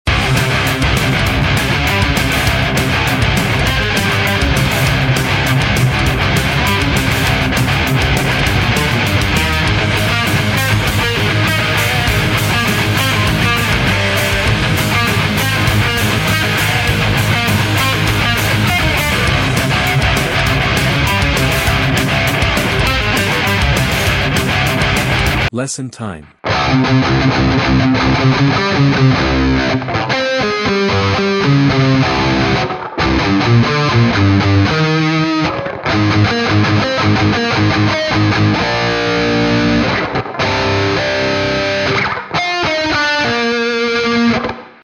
is set in C tuning